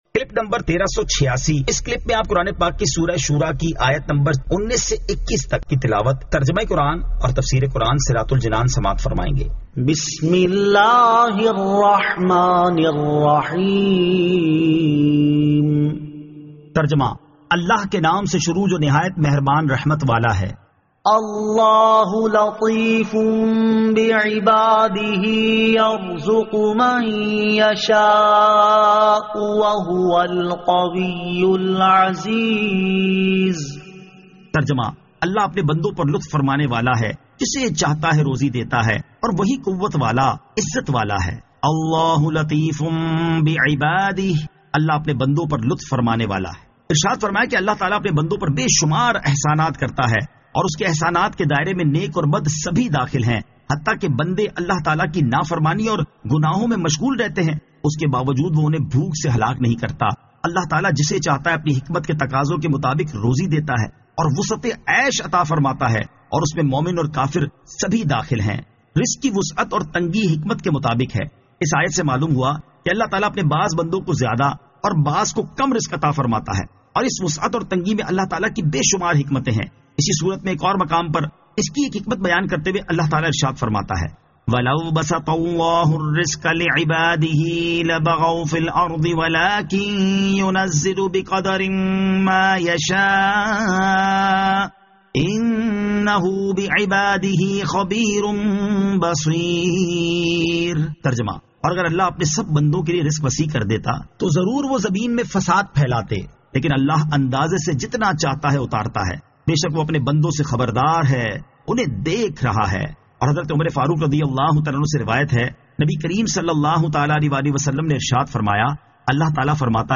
Surah Ash-Shuraa 19 To 21 Tilawat , Tarjama , Tafseer